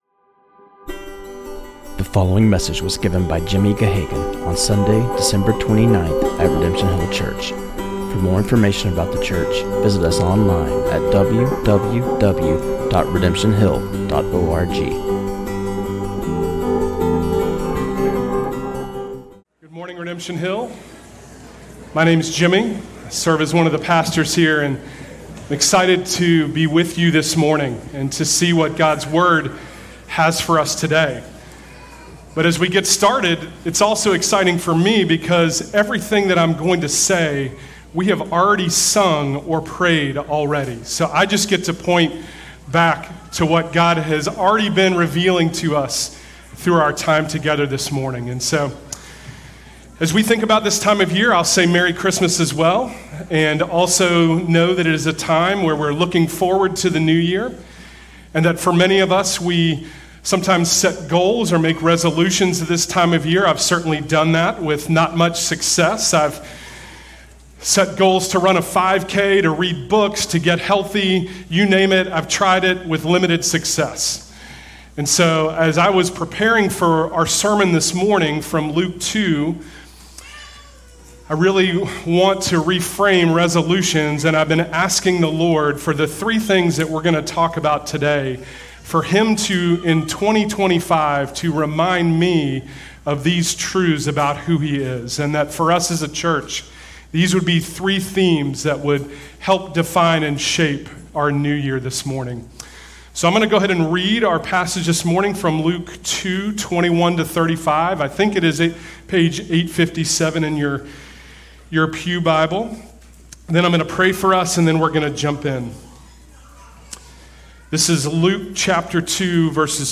This sermon on Luke 2:21-35 was preached